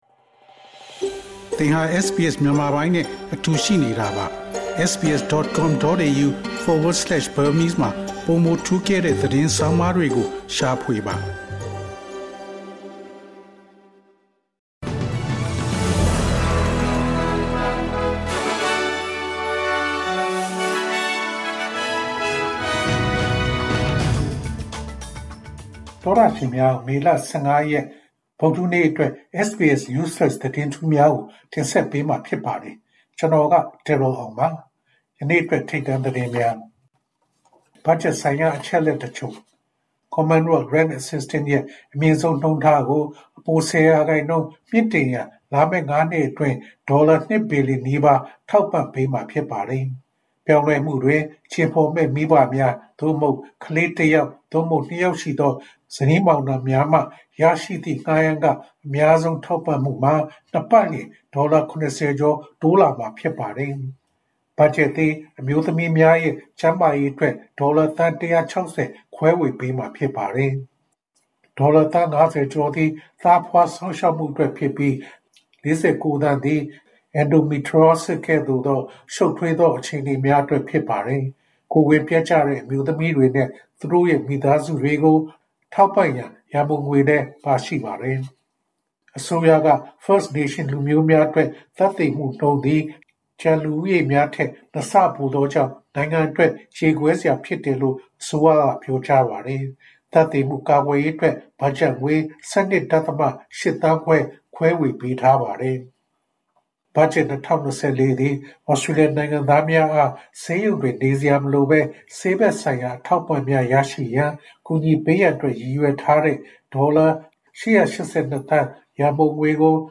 SBS မြန်မာ ၂၀၂၄ နှစ် မေလ ၁၅ ရက် News Flash သတင်းများ။